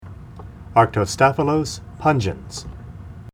Pronunciation Cal Photos images Google images
Arctostaphylos_pungens.mp3